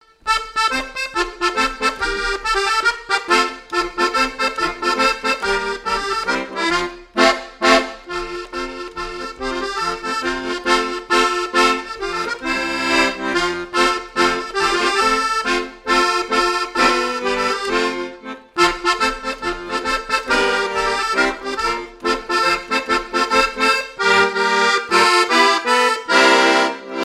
Mémoires et Patrimoines vivants - RaddO est une base de données d'archives iconographiques et sonores.
danse : ronde
Pièce musicale inédite